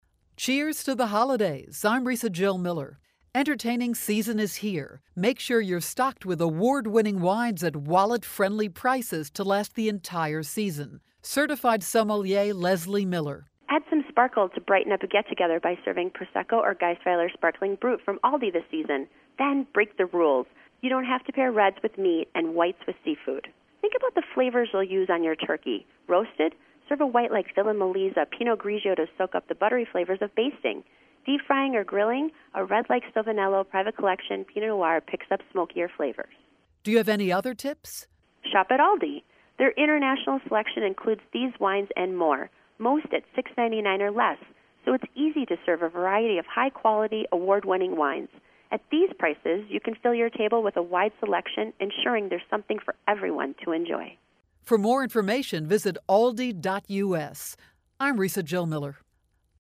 November 20, 2012Posted in: Audio News Release